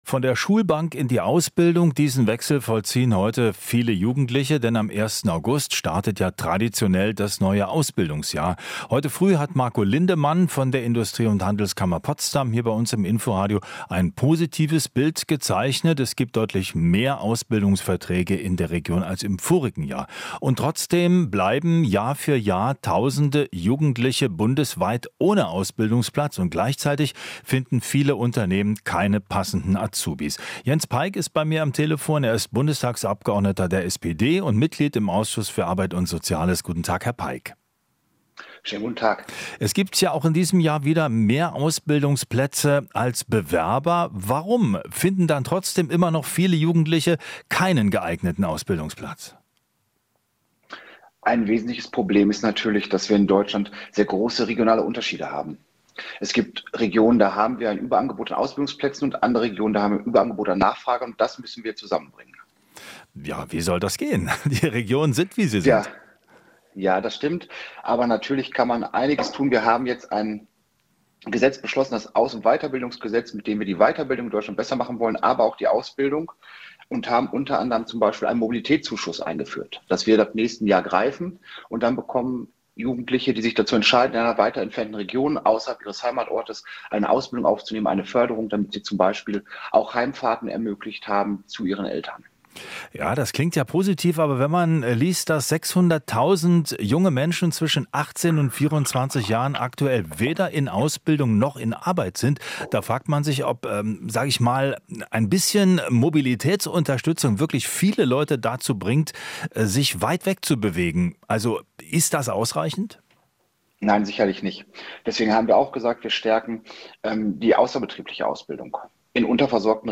Interview - Peick (SPD): "Ansehen der betrieblichen Ausbildung stärken"